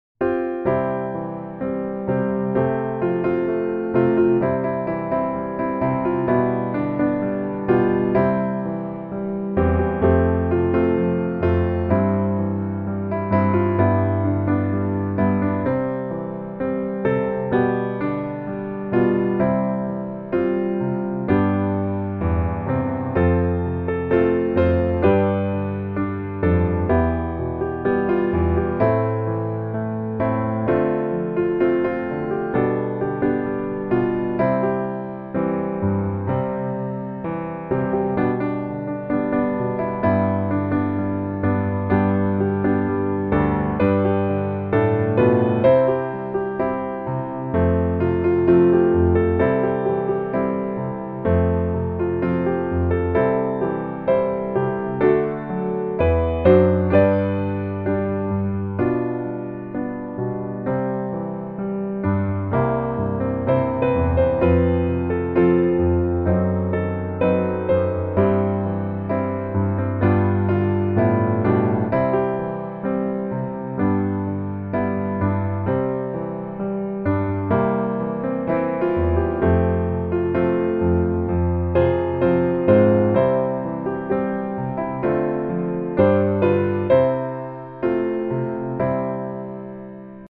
C大調